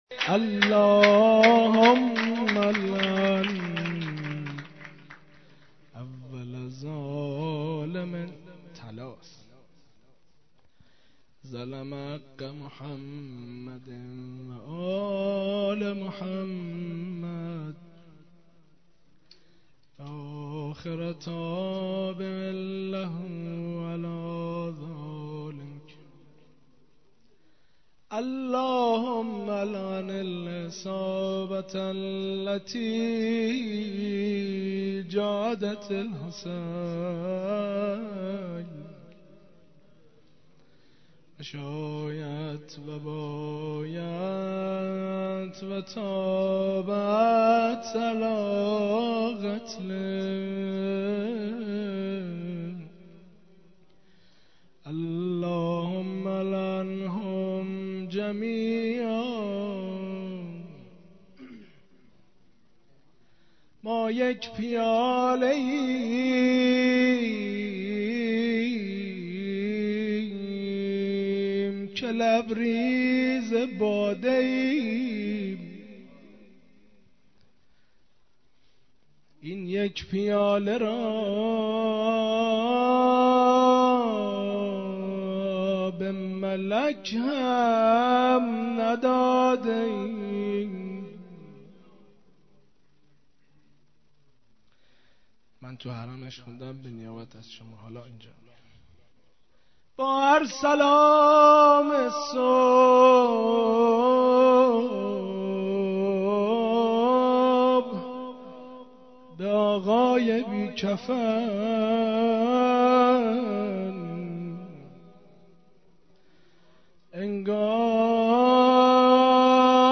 مراسم هفتگی؛ جشن میلاد امام علی النقی حضرت هادی علیه السلام؛ قسمت ششم